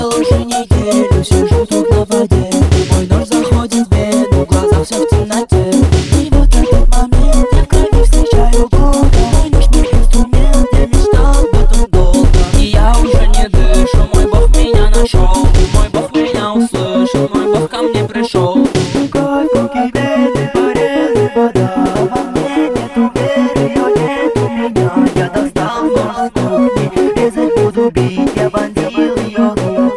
Жанр: Хип-Хоп / Рэп / Русский рэп / Русские
Hip-Hop, Rap